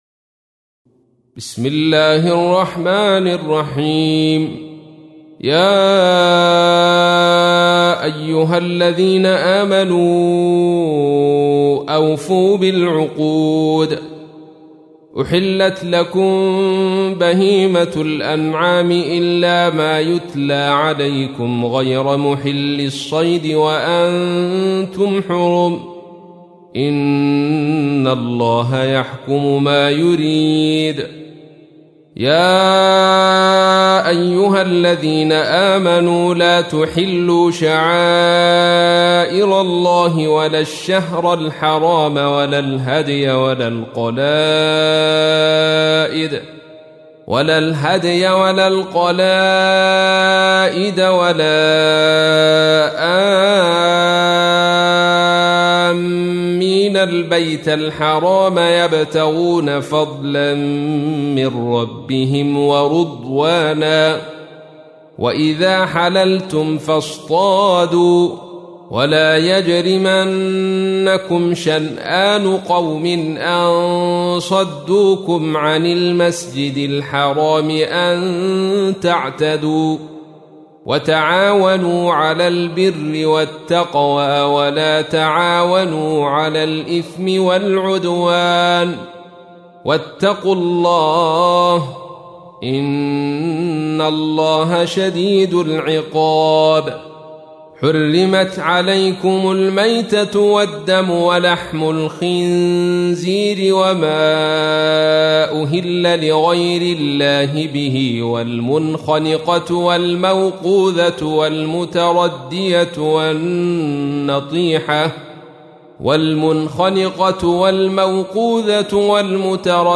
تحميل : 5. سورة المائدة / القارئ عبد الرشيد صوفي / القرآن الكريم / موقع يا حسين